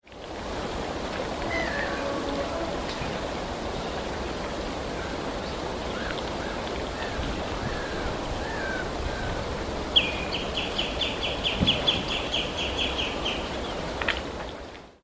Pin Mill river noises